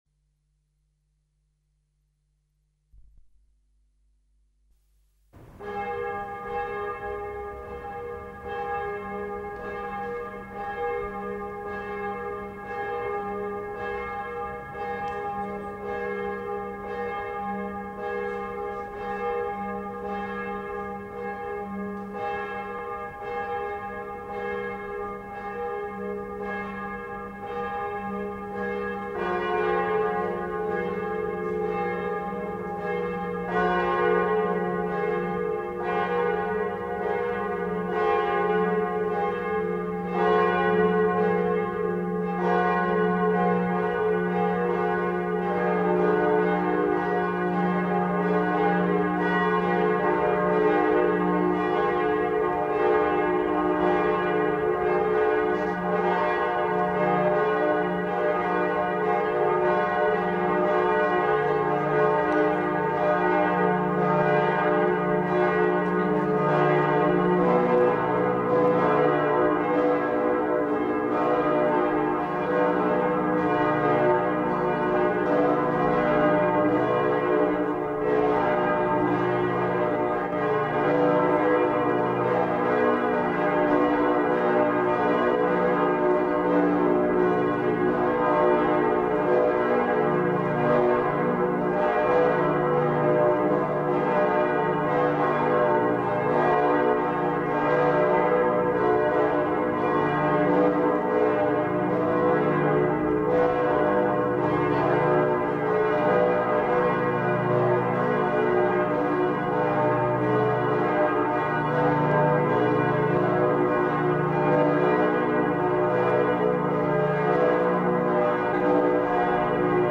Die Glocken der Herz-Jesu-Kirche
Seit Heiligabend 1990 hat die Herz-Jesu-Kirche das dritte volle Geläut mit 4 Bronzeglocken in den Tönen h-dis-fis-gis. Das sind die Anfangstöne des Chorals "Salve Regina" (Gegrüßet seist du, Königin).
01_Glocken_der_Herz-Jesu_Gemeinde_Religious.mp3